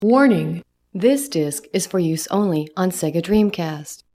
warning-this-disc-is-for-use-only-on-sega-dreamcast.mp3